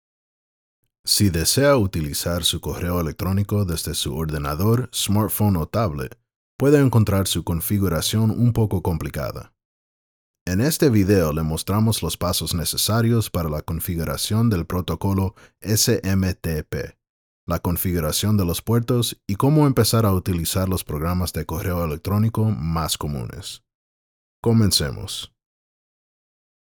Eleve la esencia de su mensaje con un servicio de voz en off profesional y puntual.
eLearning
• Sala acondicionada con variedad de trampas de graves.
• Piso de ruido de -60dB
EVO-Muestra-eLearning.mp3